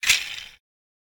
toycar.ogg